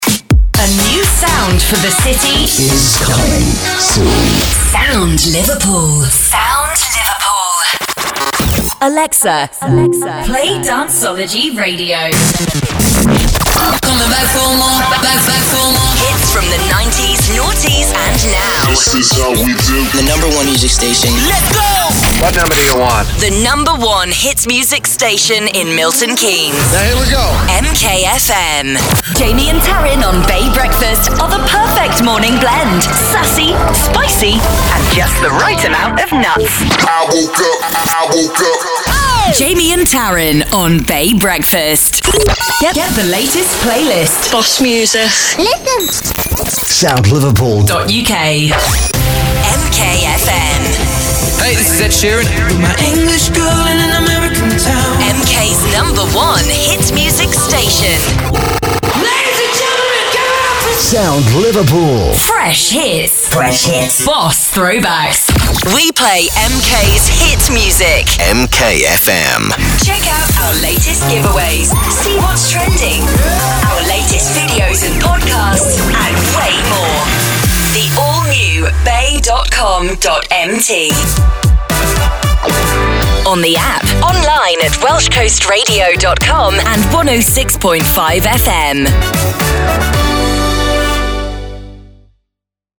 Radio Imaging Showreel
Her neutral British English accent is is warm, earthy, and grounded, with a flexible register that allows her to convincingly perform a wide range of ages.
Female
Neutral British
Bright